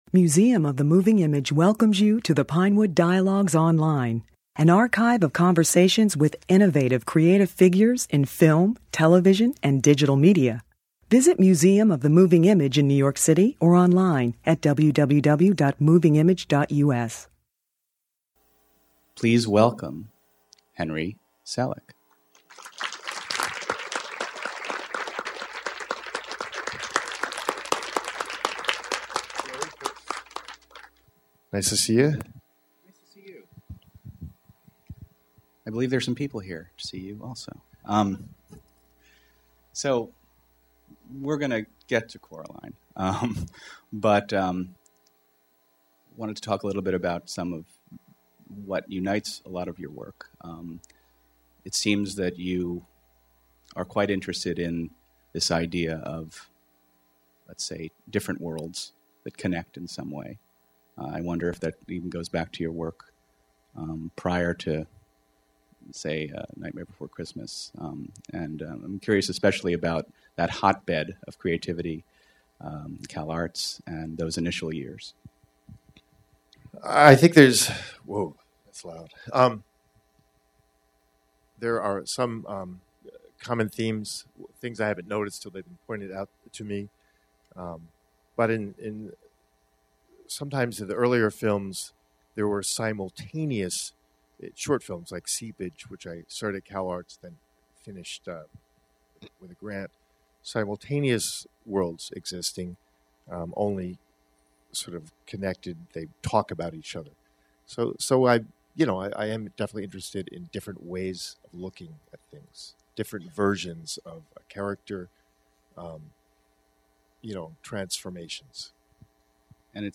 Henry Selick November 18, 2009 Henry Selick, who directed the animated features Coraline , The Nightmare Before Christmas , and James and the Giant Peach , offered an in-depth, intimate look at his early days and his career as an animator, in two programs presented by Museum of the Moving Image.